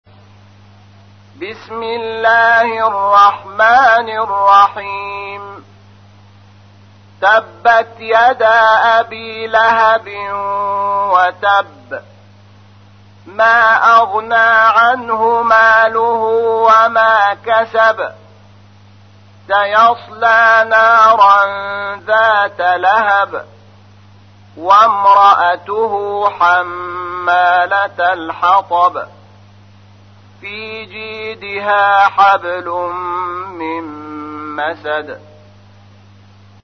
تحميل : 111. سورة المسد / القارئ شحات محمد انور / القرآن الكريم / موقع يا حسين